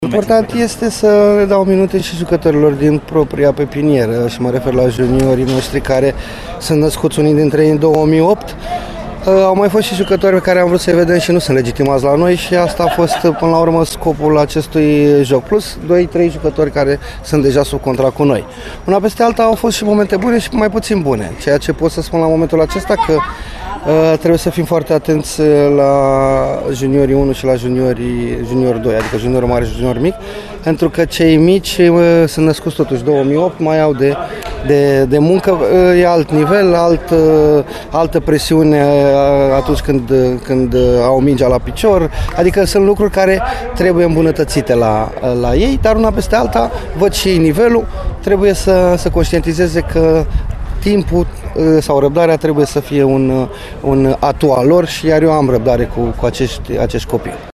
”Principalul” Flavius Stoican nu părea afectat de diferența mare dintre cele două tabere, cel puțin la capitolul ocazii create, menționând că și-a propus să-i vadă la lucru mai ales pe cei mai tineri dintre jucătorii prezenți în pregătiri: